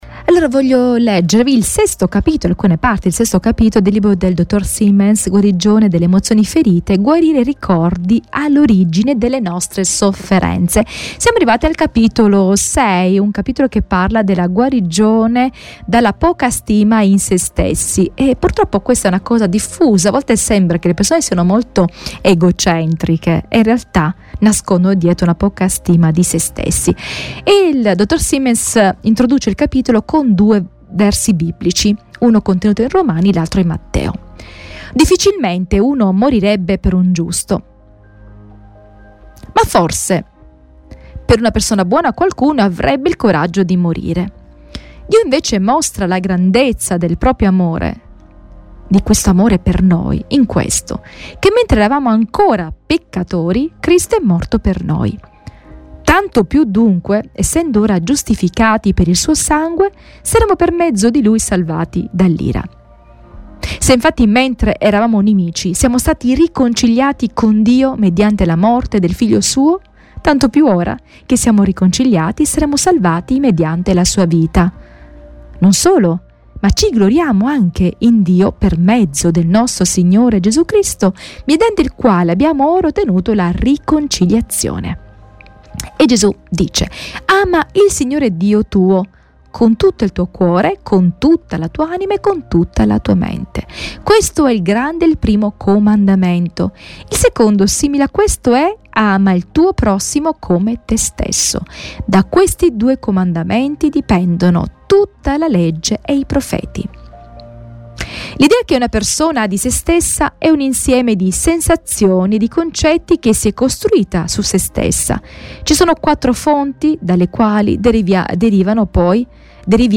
Segui la lettura dal libro di David Seamands.